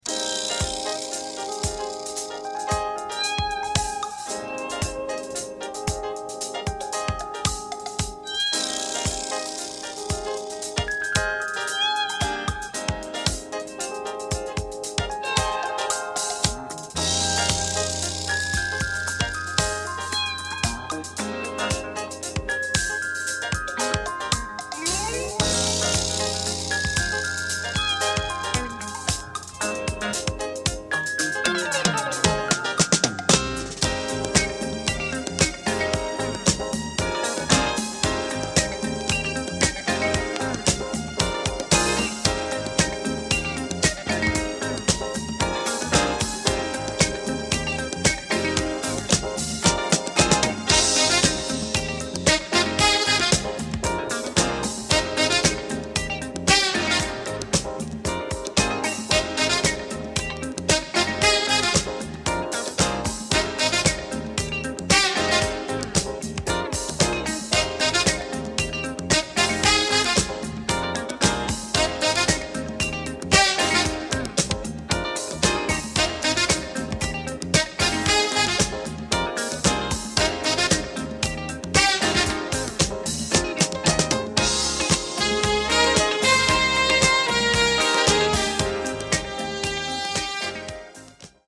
Classic Jazz-Funk featuring